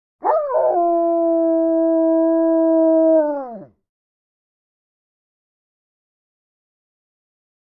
Howls | Sneak On The Lot